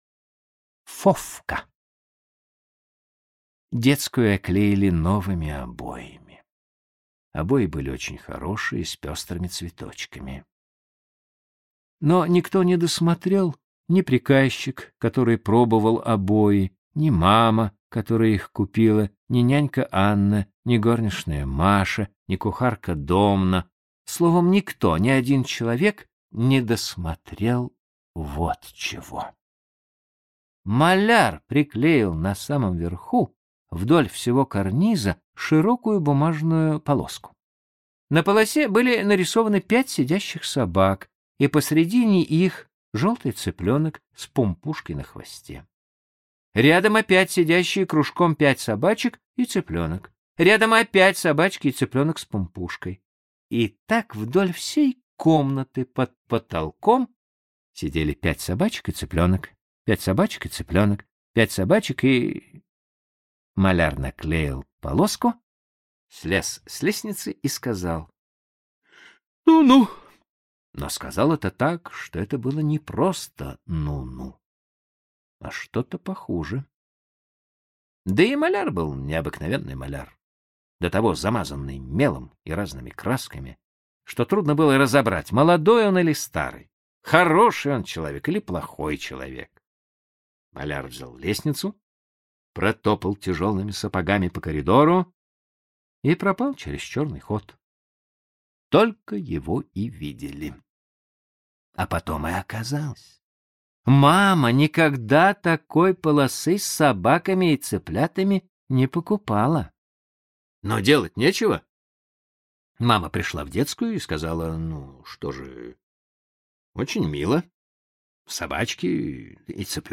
Фофка – Толстой А.Н. (аудиоверсия)
Аудиокнига в разделах